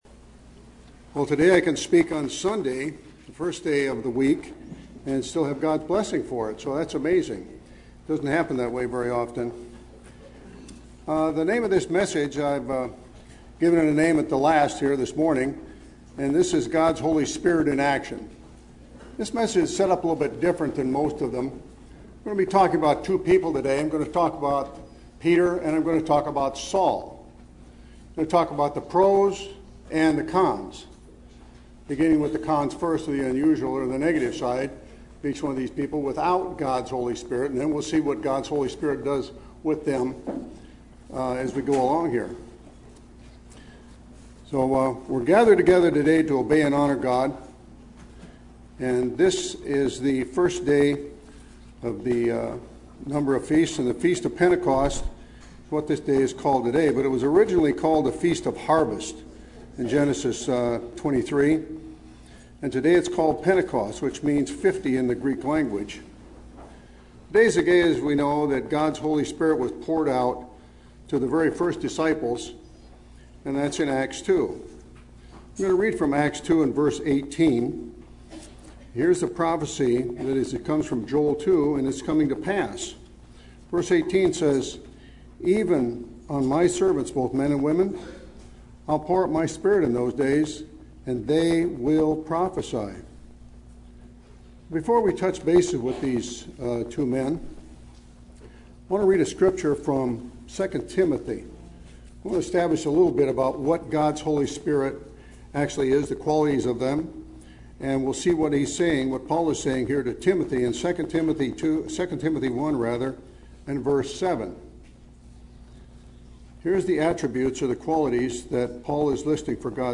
Given on the Day of Pentecost, this message recounts the events of that day, the qualities of the Holy Spirit, and then highlights the lives of Peter and Saul and the dramatic transformation that took place after they received God's Spirit.
Given in Los Angeles, CA